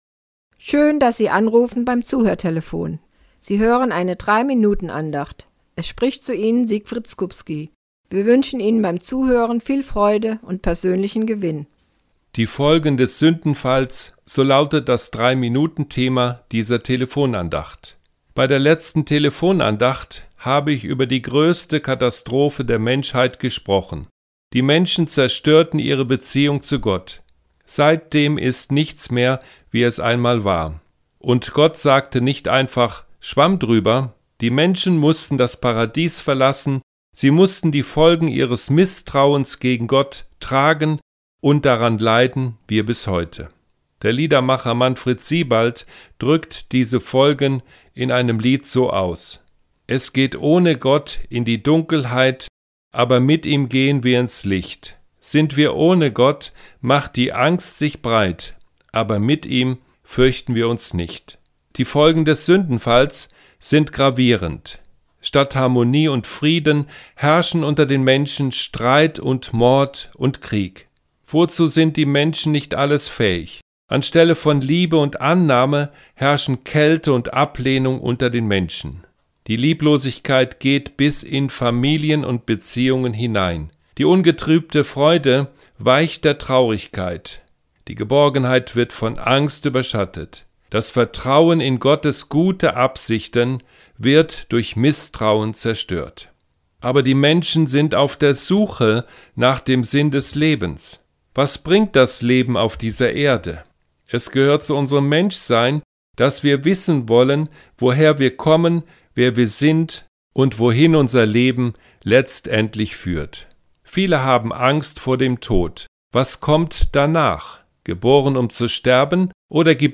WEB-ANDACHT Gönnen Sie sich einen Moment Pause und hören Sie zu.